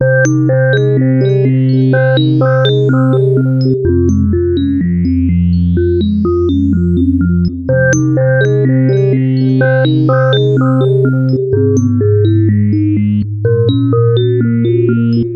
梦幻合成器
描述：在FL Studio中使用3x Osc创建的循环，背景中的吉他合唱团ah的音符相同，速度为125bpm...如果你碰巧在你的作品中加入我的任何循环，我将很高兴听到它们
Tag: 125 bpm Chill Out Loops Synth Loops 1.29 MB wav Key : Unknown